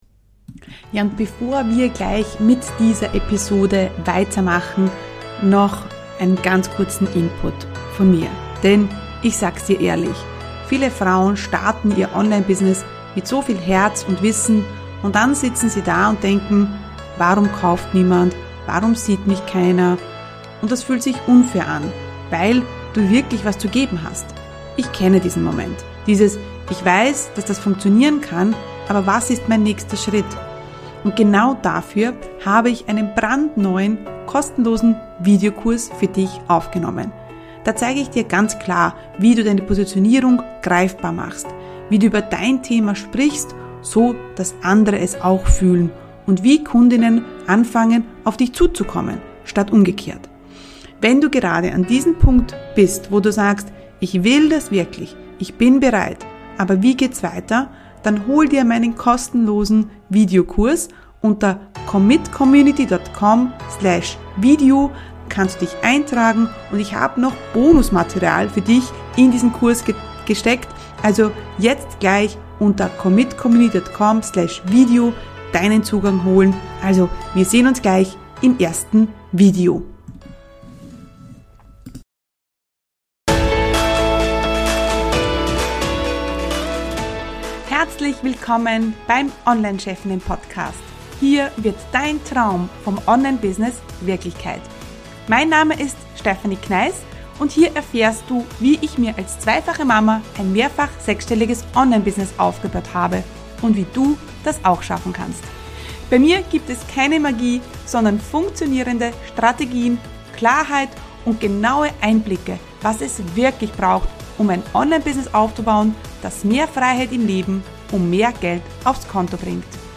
353 Interview: Frauen verkaufen anders ~ Online Chefinnen Podcast